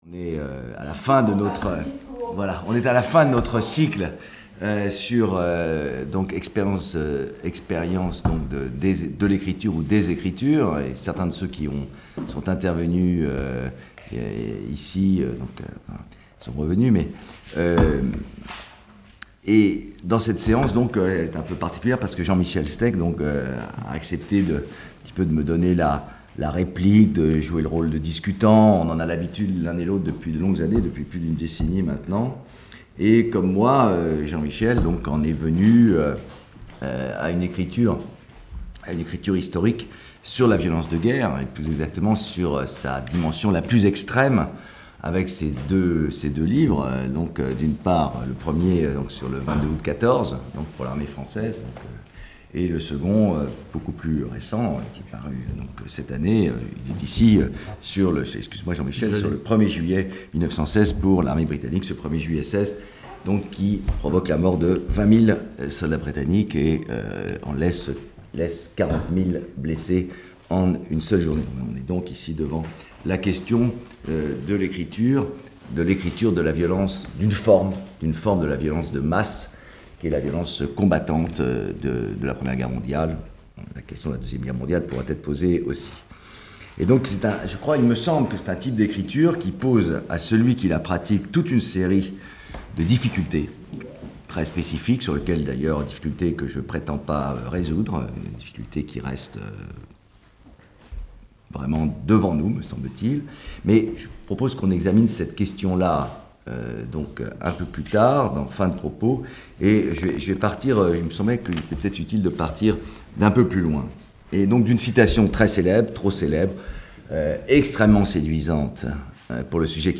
Stéphane Audoin-Rouzeau en discussion